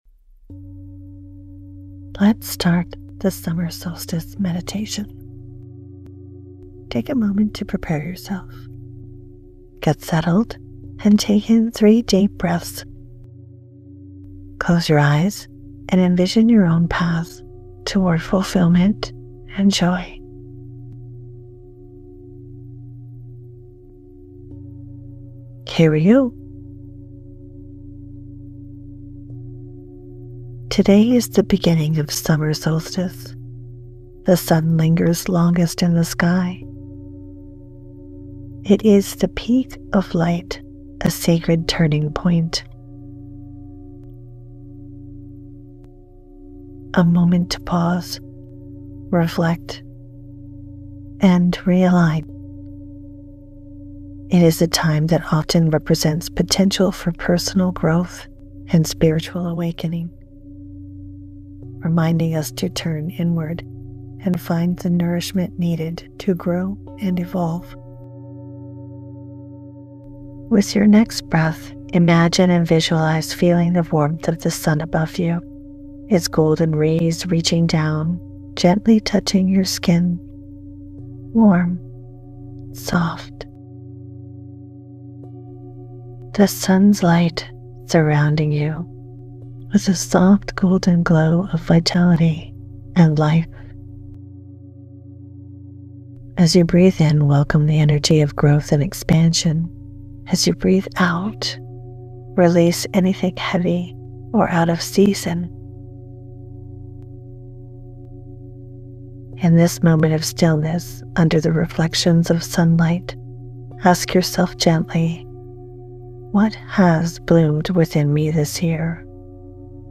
Celebrate the longest day of light with this gentle guided meditation, created to help you pause, reflect, and realign with the rhythm of the season. This special Summer Solstice moment invites you to honor your growth so far, release what no longer serves you, and set heart-led intentions for the second half of the year.